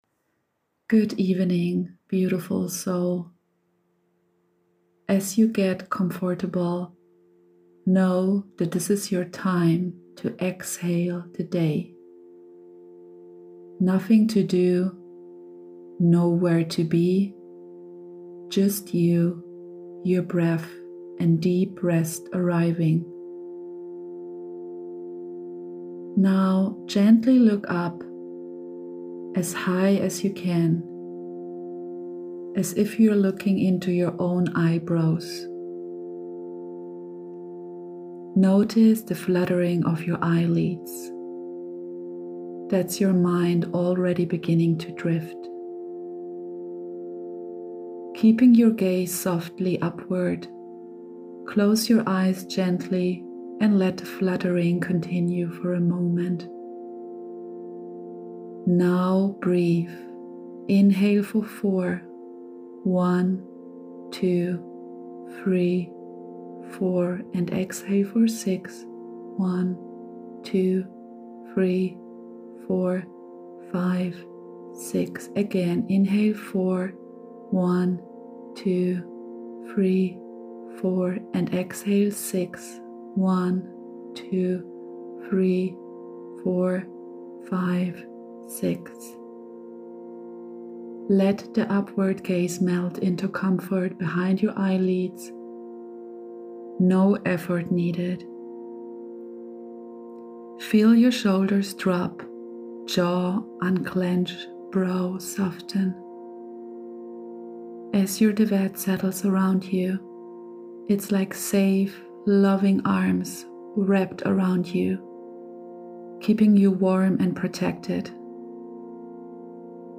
Your Good Night Meditation is ready!